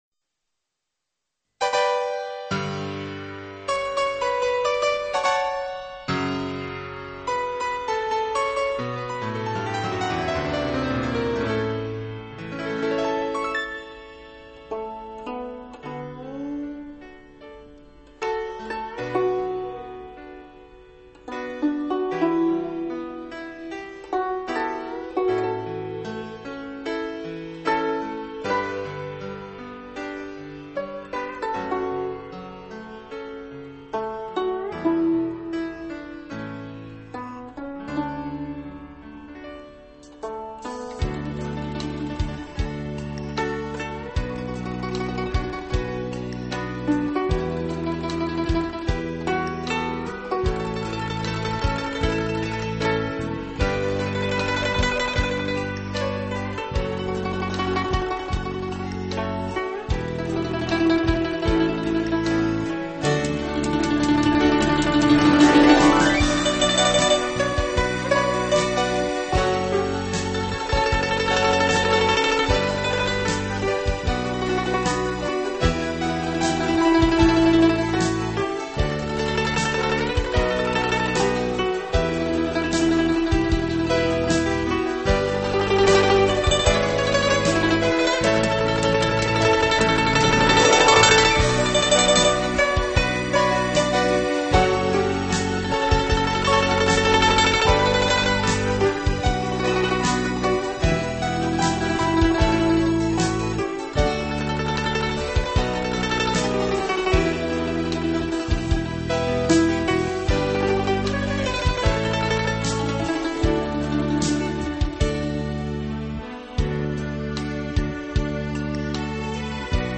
纯音乐
悠扬如诗的清新乐曲
仿似天籁之声